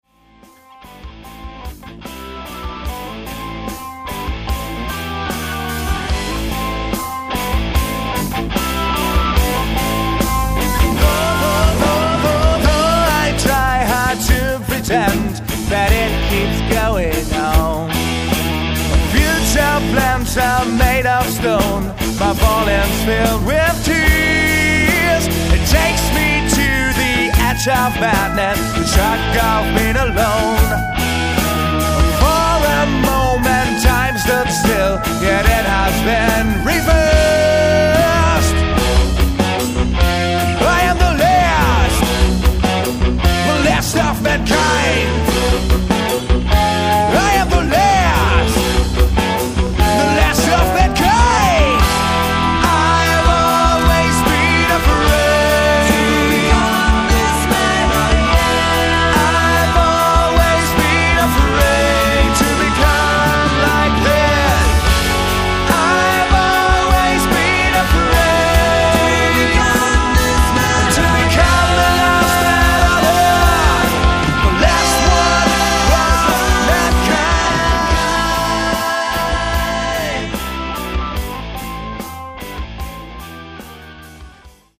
electric & acoustic guitars, vocals
bass, vocals, horns
keyb., organ, moog, vocals
drums, perc
hard-sabbath-riff-pseudo-hymne